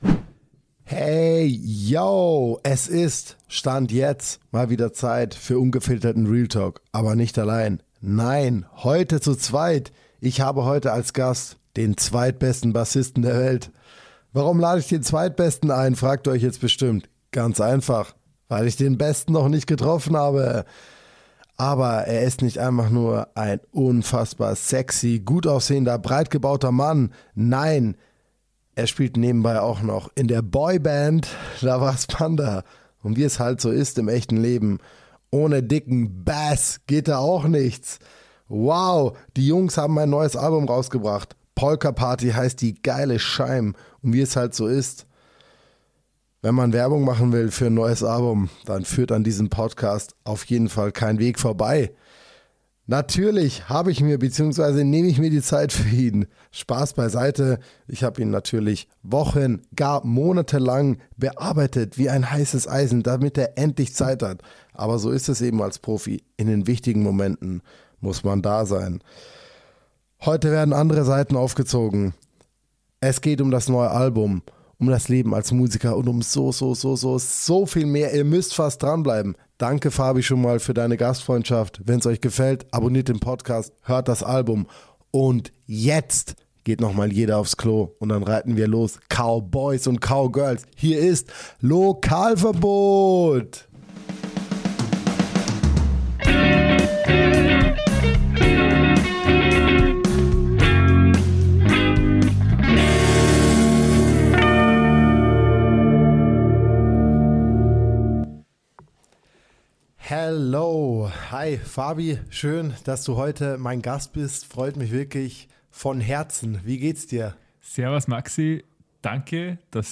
Heute werde ich mal vom Host zum Groupie , denn ich habe einen ganz besonderen Gast: Einen der besten, schönsten und vor allem sympathischsten Bassisten der Welt – und heute gibt er sich endlich die Ehre, mein Gast zu sein.